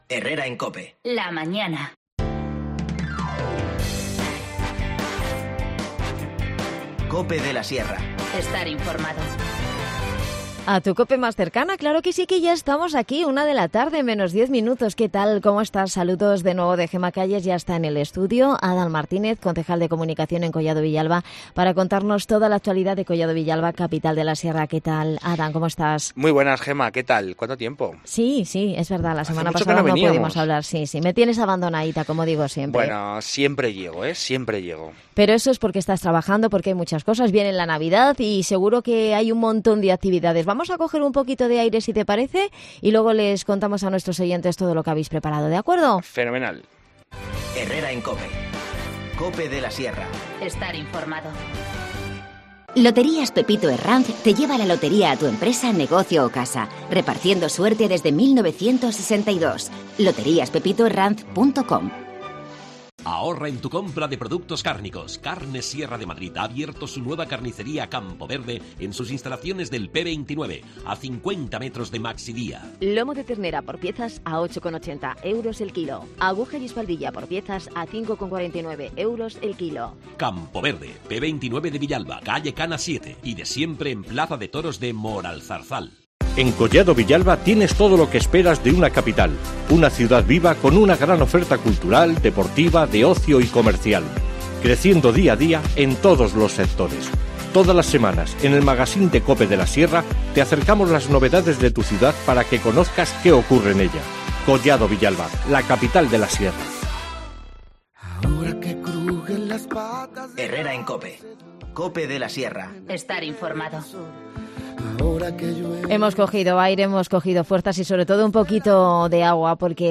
AUDIO: Repasamos la actualidad de Collado Villalba Capital de La Sierra con Adan Martínez, concejal de Comunicación.